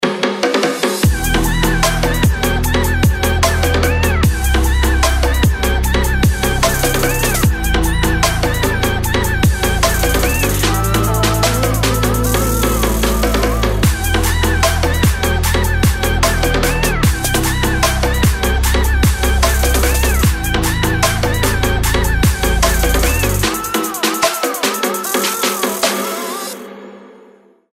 • Качество: 320, Stereo
поп
dance
RnB
Очень красивая свежая электронная музычка